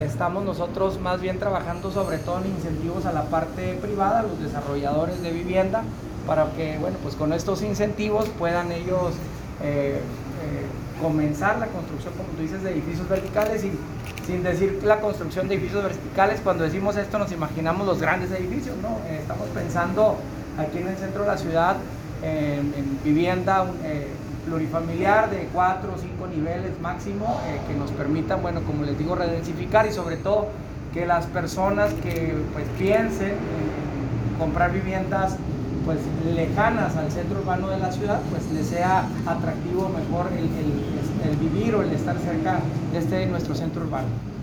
Audio. Marco Bonilla Mendoza, alcalde de Chihuahua.